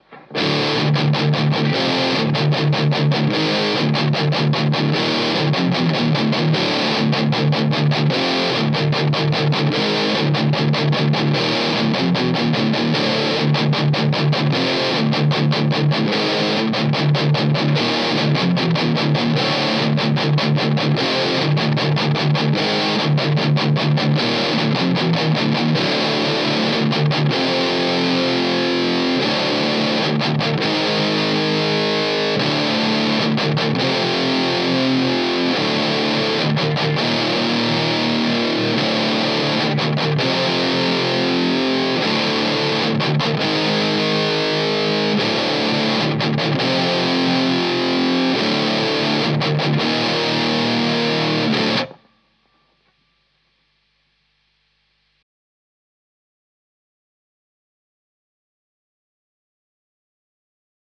OK, here's some very high gain tones using a Boss DS1 and a Boss SD1 - both should be cheap and easily available in Kathmandu. The DS1 is set with the level and tone both at about 12 O'Clock.
This compresses it all and tightens up the bass end so it doesn't turn into too much of a mushy mess. Low volume is TV volume, High Volume is my normal recording volume.